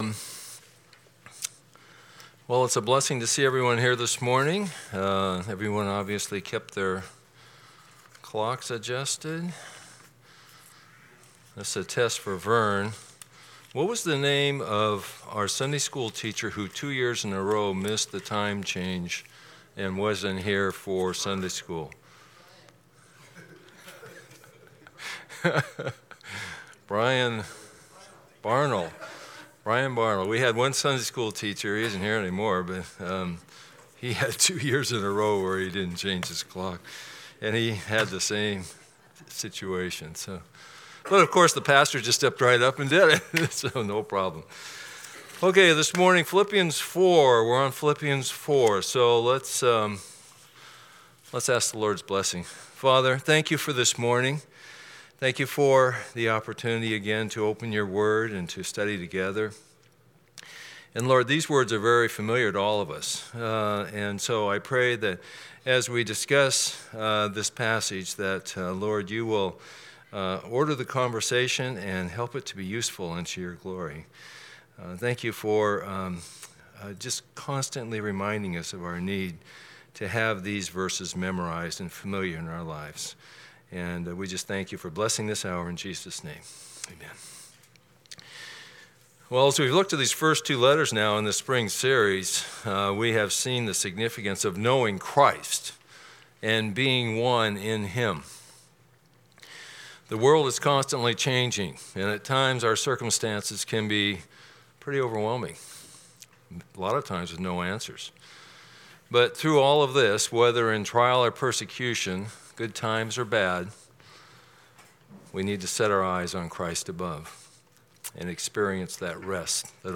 Philippians 4 Service Type: Sunday School Download Files Bulletin « Lesson 8